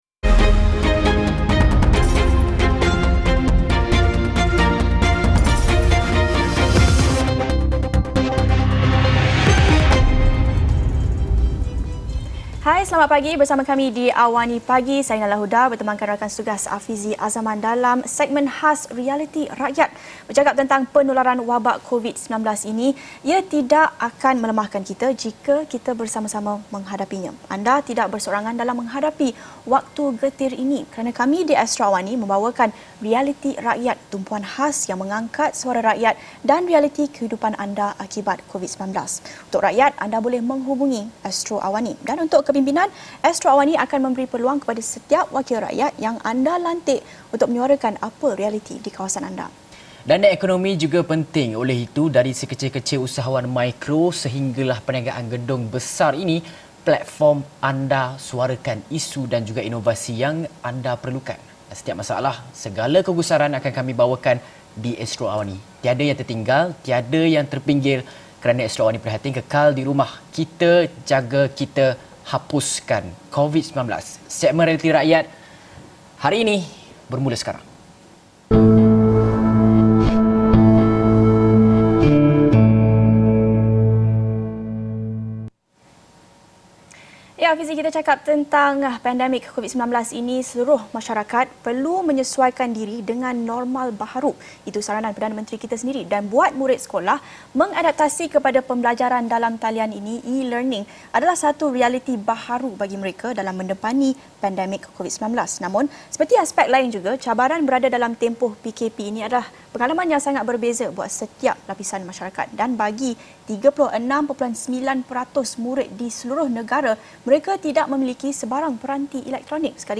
Antara isu yang dikupas termasuk askes masalah tiada peranti yang dihadapi hampir 40 peratus murid-murid yang sekali gus menyebabkan akses pendidikan yang tidak sama rata. Episod ini telah disiarkan secara langsung dalam program AWANI Pagi, di saluran 501, jam 8:30 pagi.